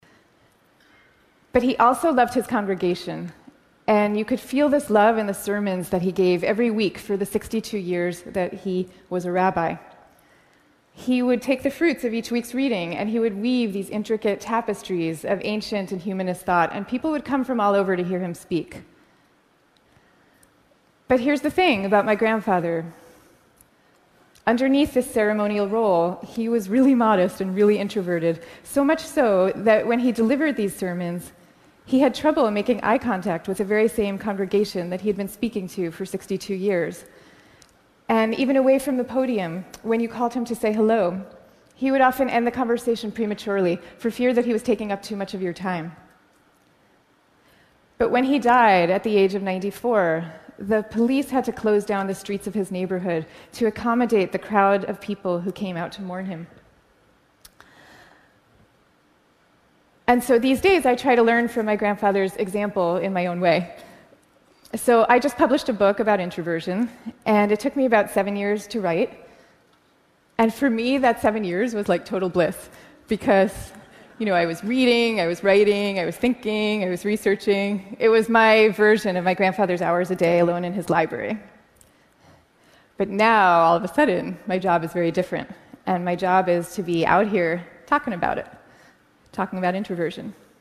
TED演讲：内向性格的力量(10) 听力文件下载—在线英语听力室